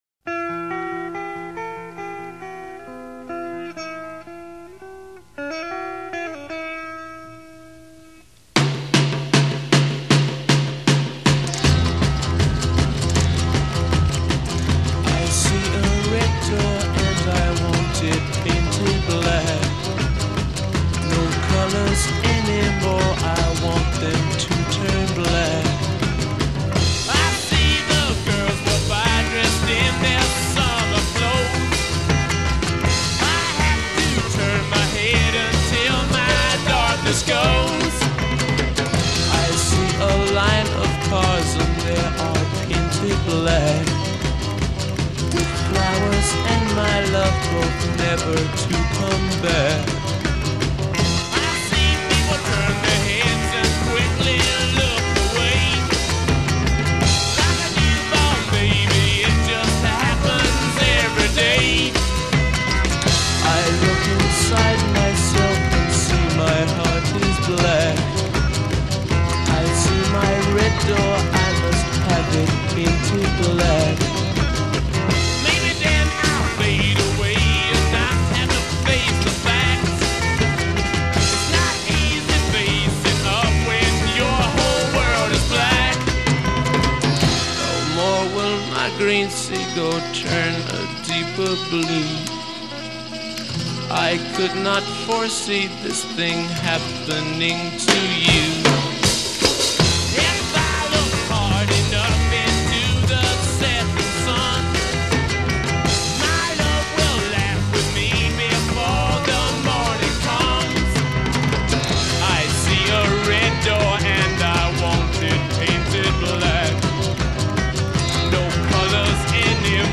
Recorded 6-9 March 1966, RCA Studios, Hollywood.
Intro   0:00   Guitar outlines main melody in free time.
"   0:   Drums mark a straight four beats per measure. a
Sitar drops out. Guitar to front of mix.
part     Repeat pattern, first with humming, then with text.
Repeat and fade.